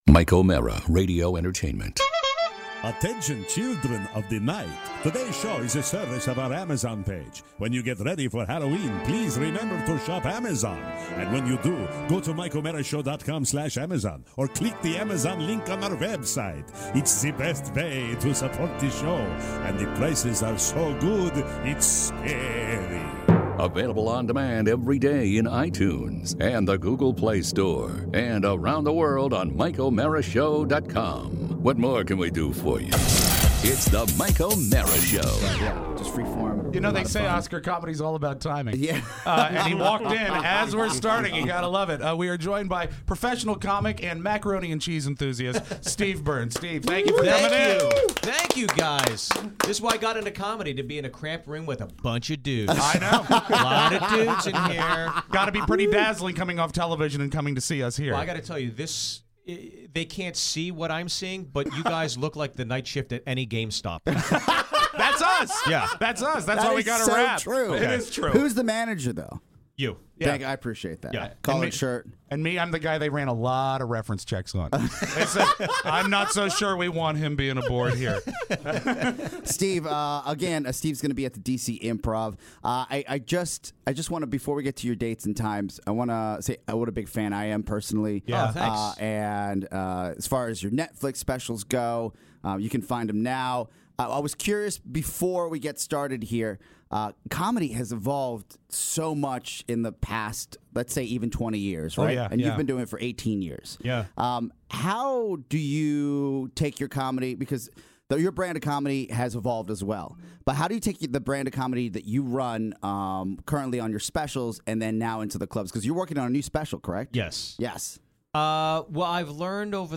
Comedian Steve Byrne in studio!